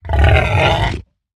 Minecraft Version Minecraft Version 25w18a Latest Release | Latest Snapshot 25w18a / assets / minecraft / sounds / mob / hoglin / retreat3.ogg Compare With Compare With Latest Release | Latest Snapshot
retreat3.ogg